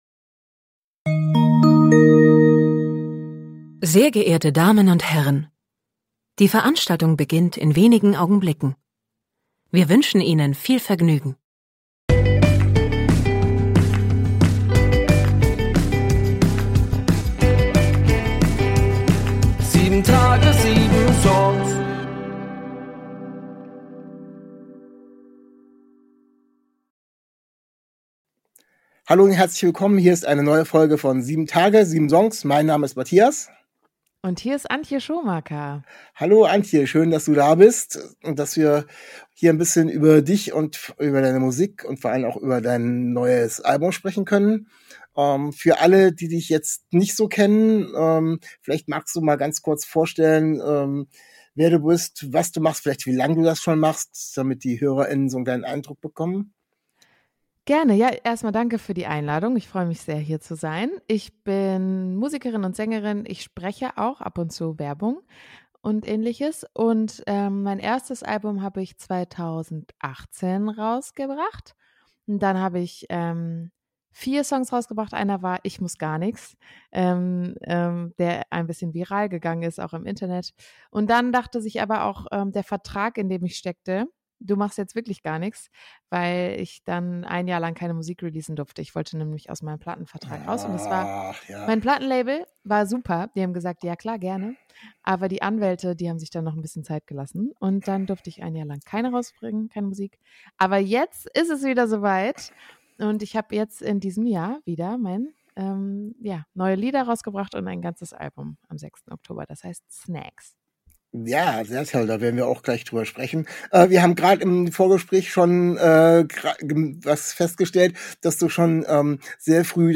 Zum Interview ist die Indie- Pop Künstlerin ANTJE SCHOMAKER zu Besuch.